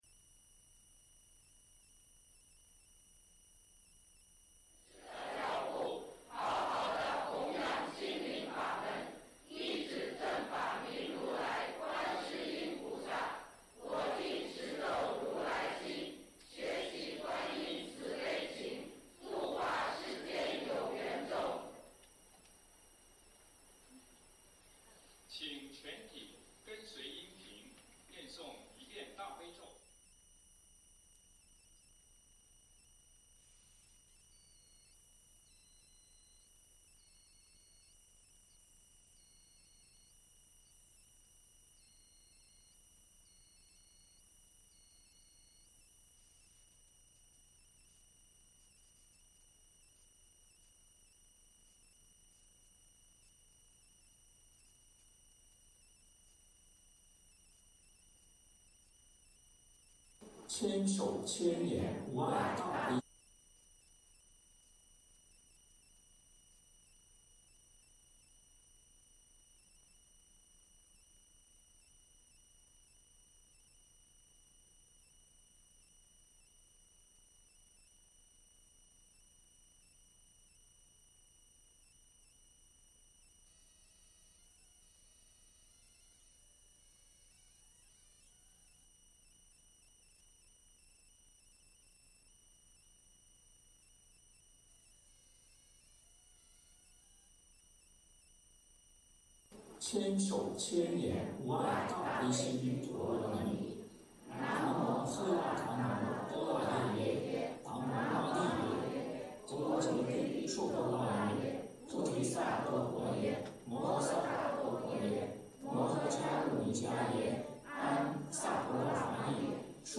恩师圆寂 追思赞颂典礼 【实况仪式片段】2021年11月15日